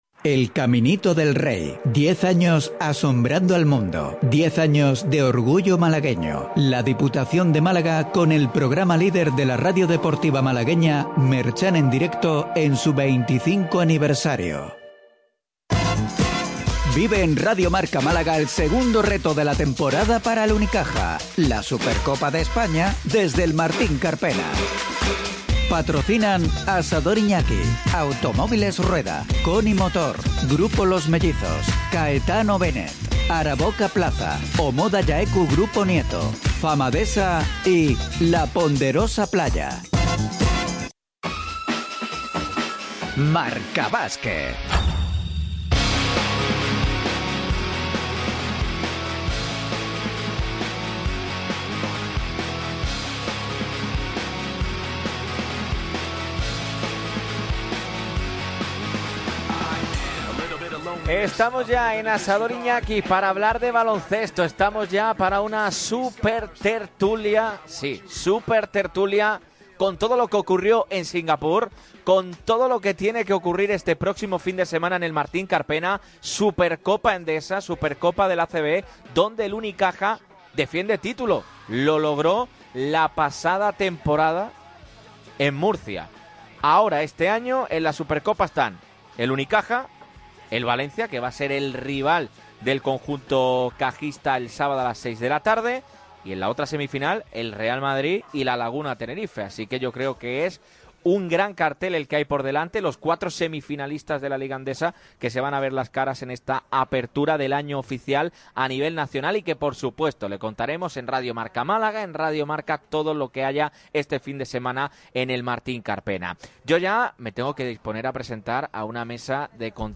Asador Iñaki y Radio MARCA Málaga se alinearon este jueves 25 de septiembre para la tertulia previa de la Supercopa Endesa que se disputa en el Carpena este fin de semana.